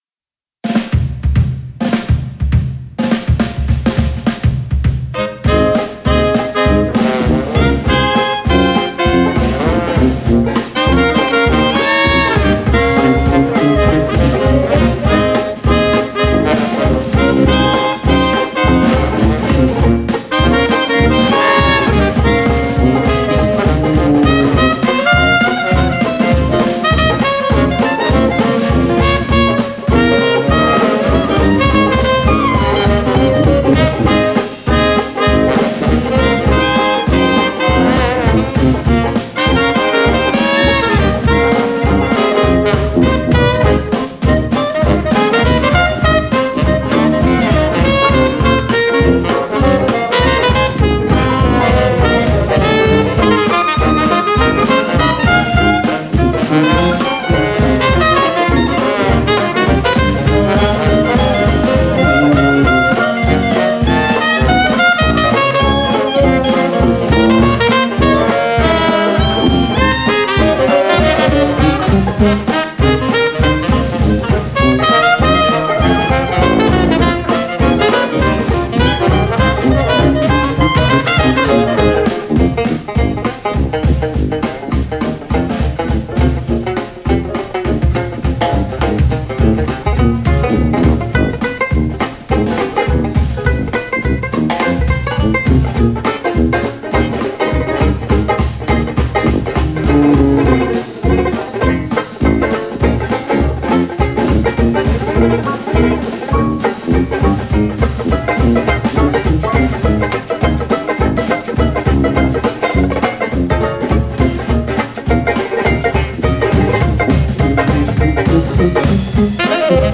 Jumping into the more swinging side of Christmas Music.
Traditional Jazz/Jam riff